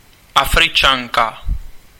Ääntäminen
Ääntäminen France (Aquitaine): IPA: /a.fʁi.kɛn/ Haettu sana löytyi näillä lähdekielillä: ranska Käännös Ääninäyte 1.